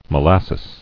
[mo·las·ses]